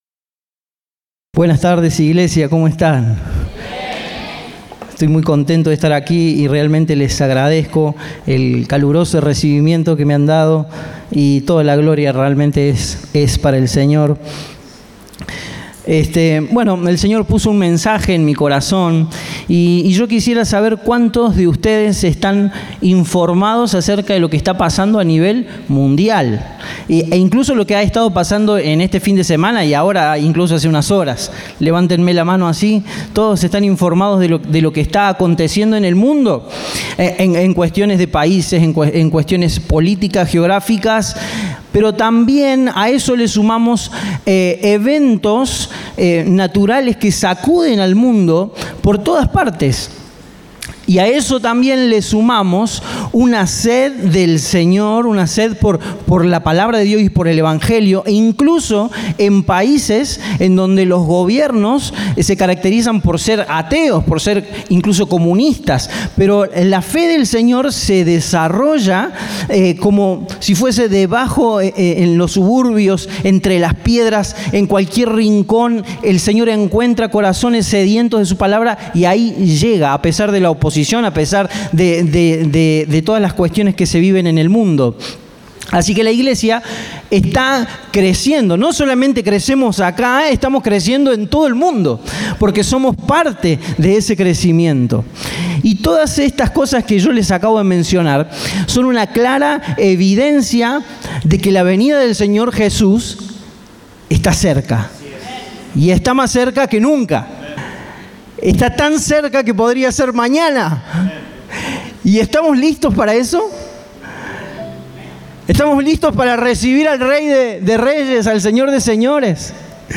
Compartimos el mensaje del Domingo 1 de Marzo de 2026.